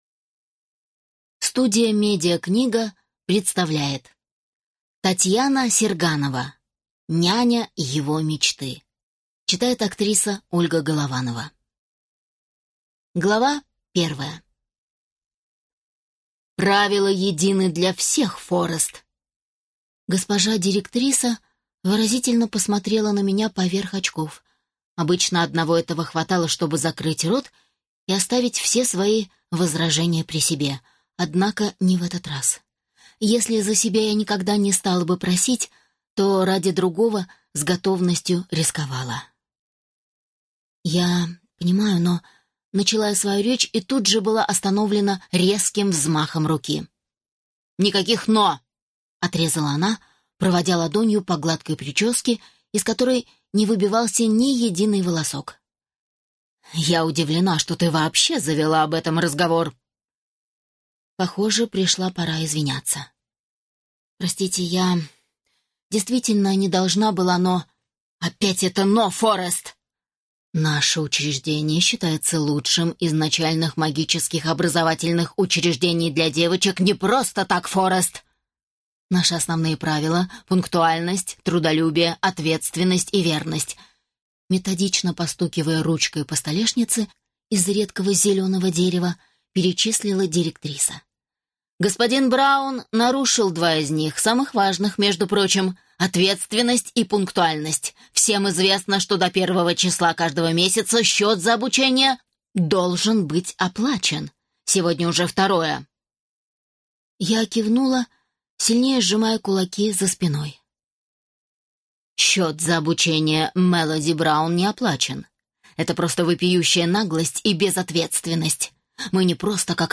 В долине солнца (слушать аудиокнигу бесплатно) - автор Энди Дэвидсон